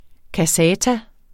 Udtale [ kaˈsæːta ]